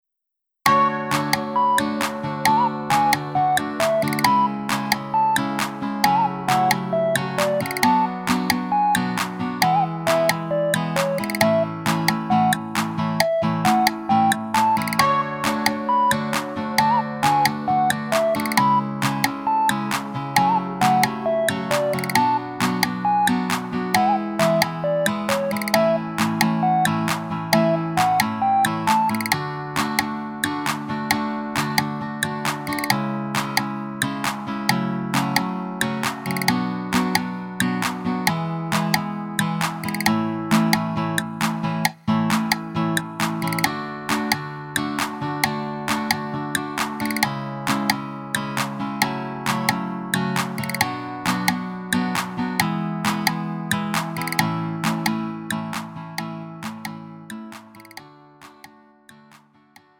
음정 원키 3:23
장르 가요 구분 Lite MR